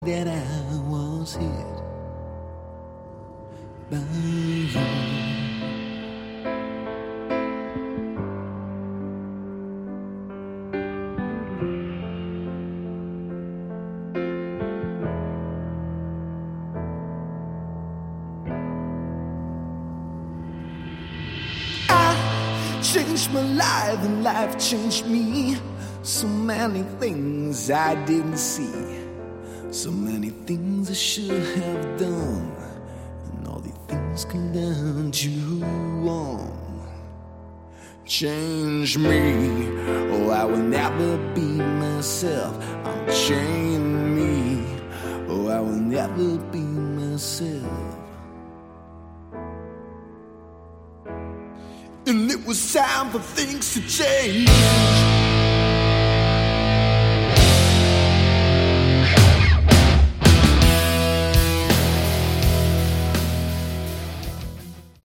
Category: Hard Rock
guitar, vocals
keyboards, Hammond organ